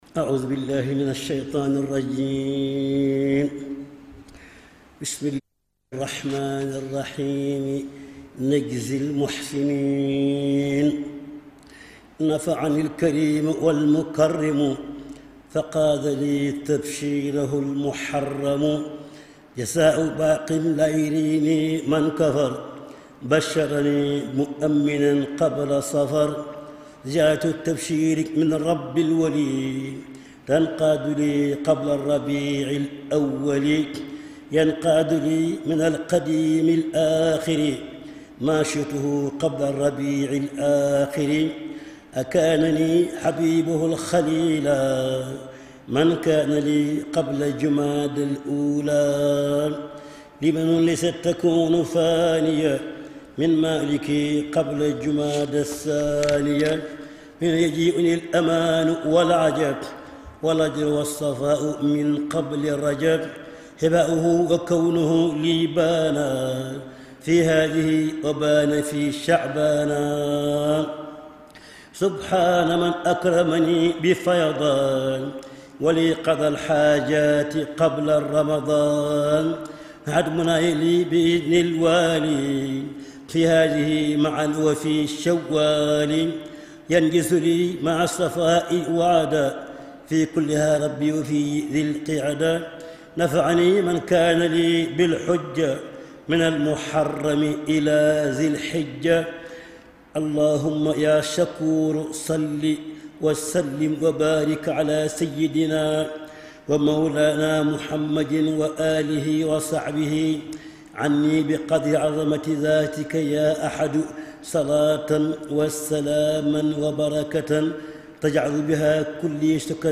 Khassida